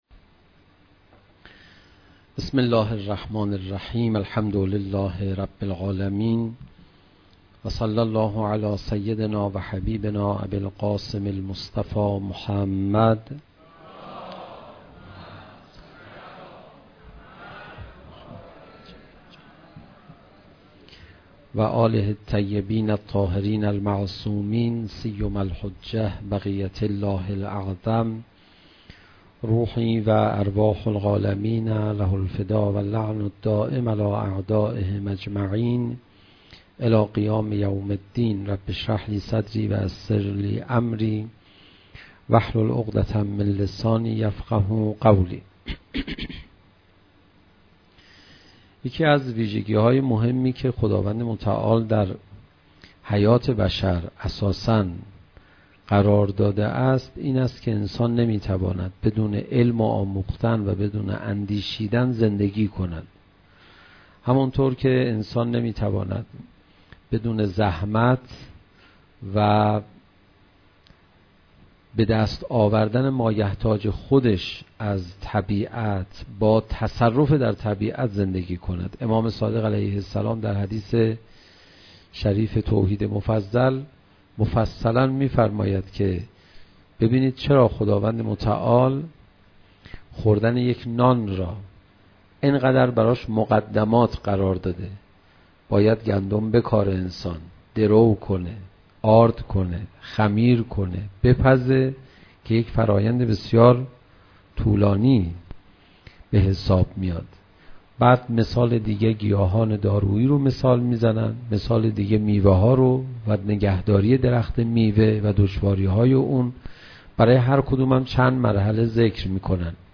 سخنرانی حاج آقا پناهیان درهمایش سیرمطالعاتی اندیشه های امام خمینی(ره) درخانه بیداری